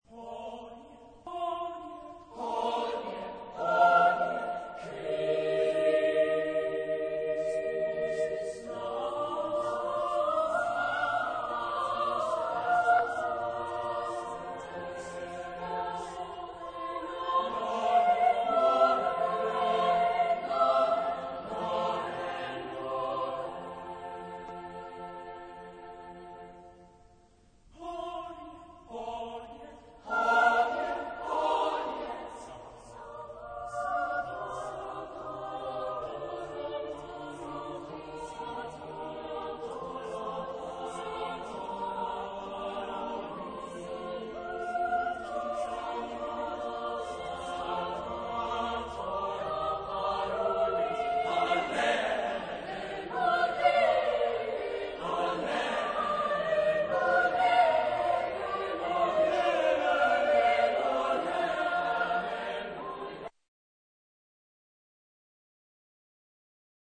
Genre-Style-Form: Sacred ; Motet
Type of Choir: SSATB  (5 mixed voices )
Instruments: Organ (1)
Tonality: B flat major
sung by Drakensberg Boys' Choir